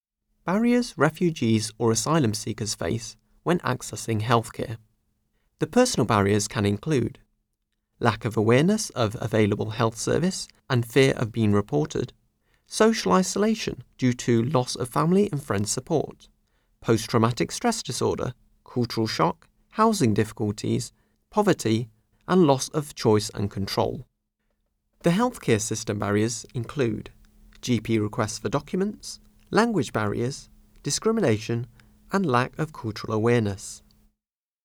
Narration audio (MP3) Narration audio (OGG) Select which of the following statements are true or false?